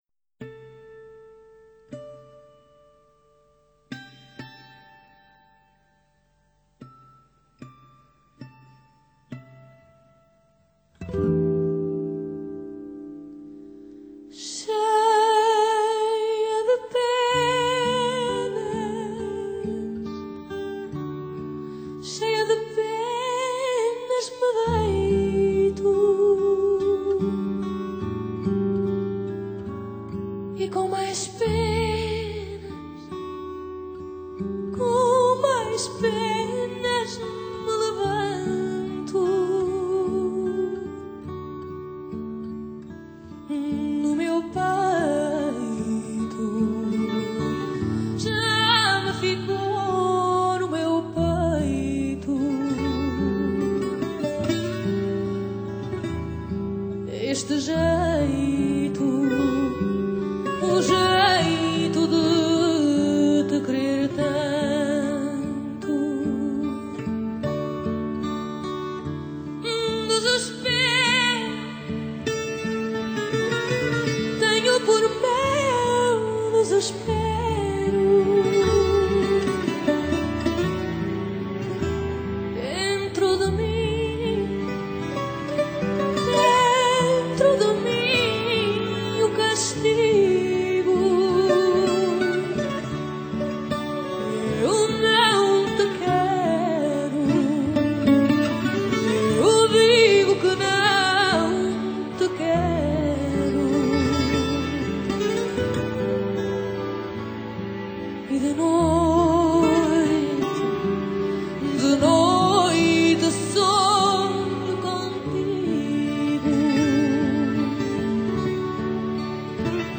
【类型】Fado
★ 甜美有特色的嗓音，又有大型管弦乐团伴奏，加上超水准的录音，凡人岂能不动心。